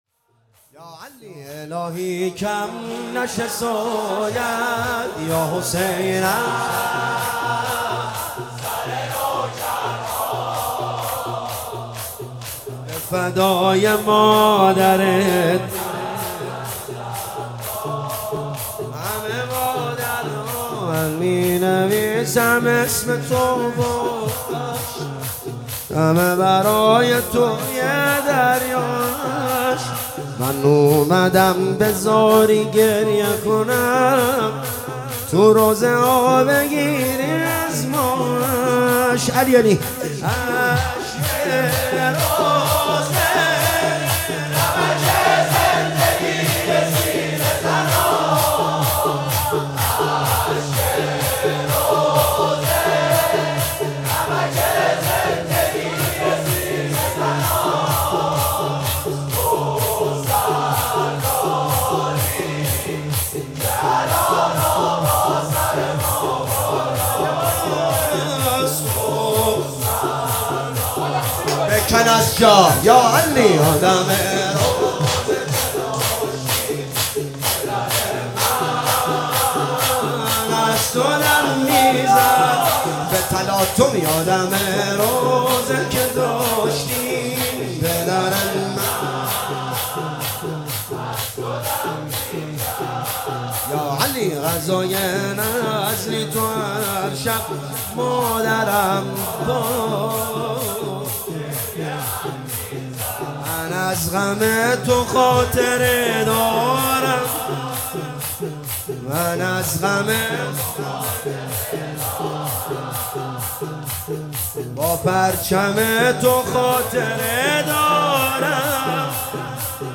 هیئت حضرت حسن بن علی اصفهان